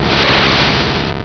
pokeemerald / sound / direct_sound_samples / cries / kadabra.aif